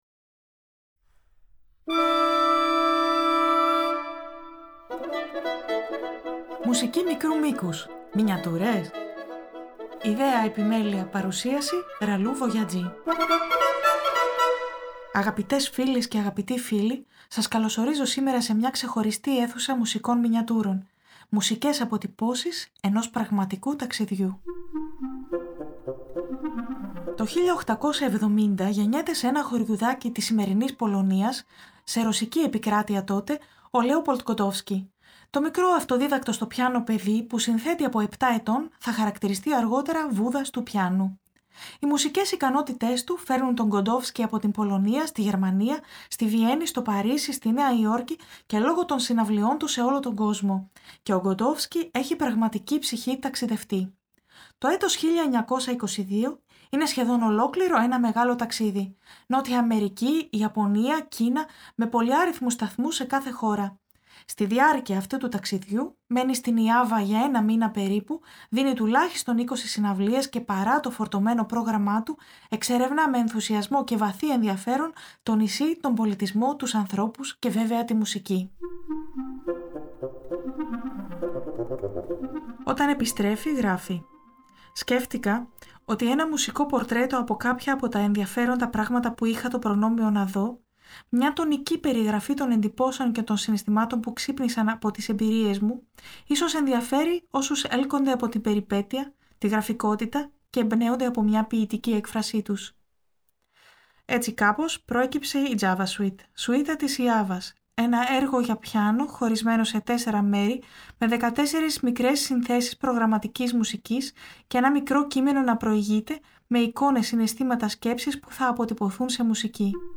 Η ‘’Μουσική μικρού μήκους-Μινιατούρες;‘’ στις καθημερινές μικροσκοπικές και εβδομαδιαίες θεματικές εκπομπές προσκαλεί σε περιηγήσεις σε μια φανταστική έκθεση- σε μια ‘’ιδιωτική συλλογή’’ -μουσικών έργων ‘’μικρού μήκους’’ από όλες τις εποχές και τα είδη της αποκαλούμενης δυτικής ευρωπαϊκής λόγιας και συγγενών ειδών: